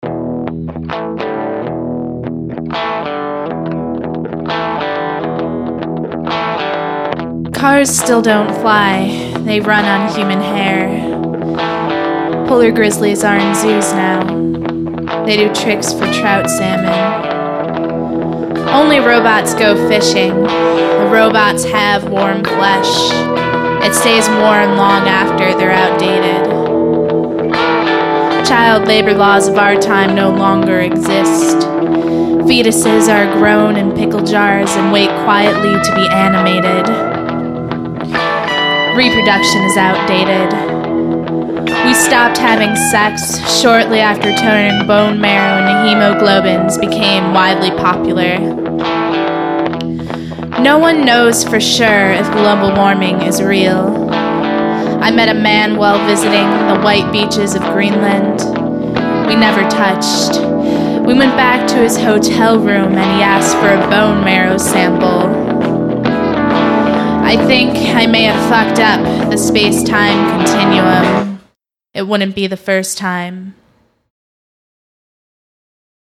Spoken Word 2.mp3